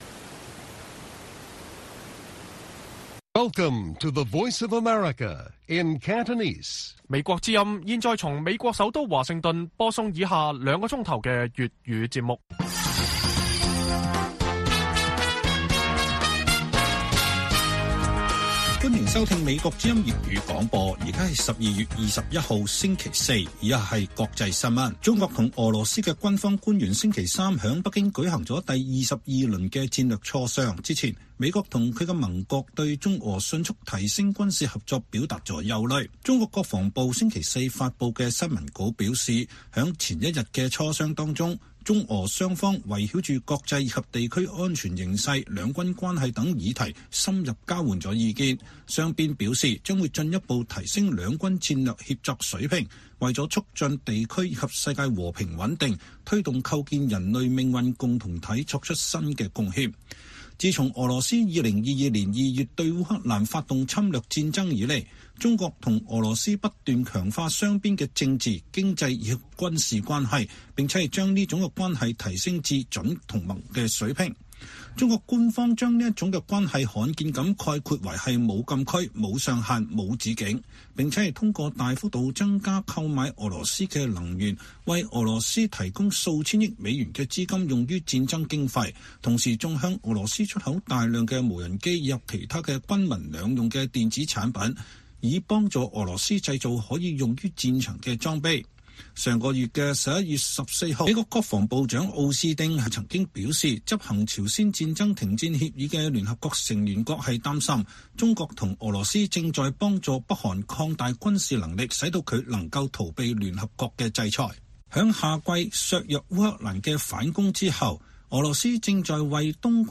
粵語新聞 晚上9-10點 : 前支聯會領袖鄒幸彤煽顛案還柙逾兩年 高院申保釋被拒 預料2024下半年開審